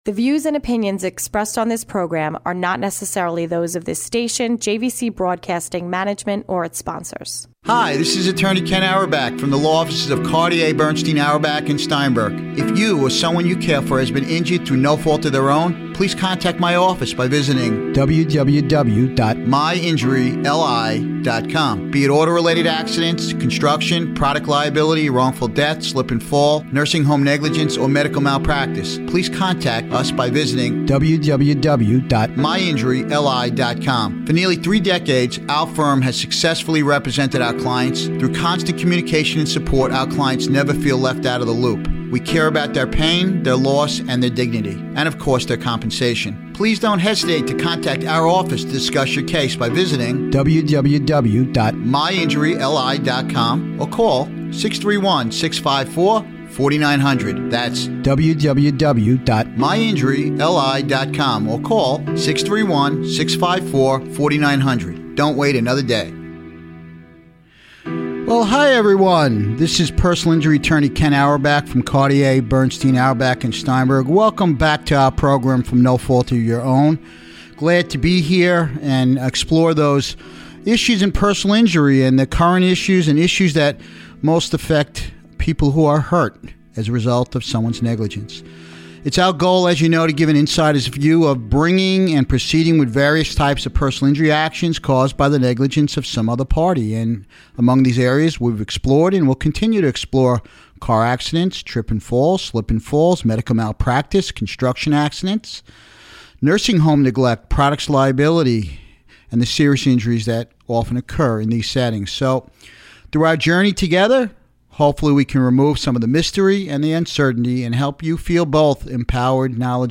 Special Guest Orthopedic Surgeon